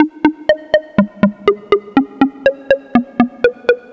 Just Analogue Eb 122.wav